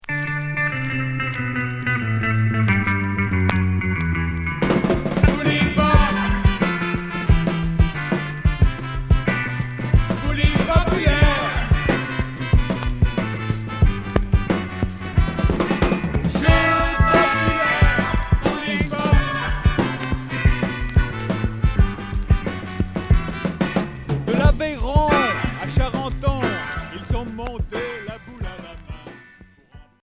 Jerk accordéon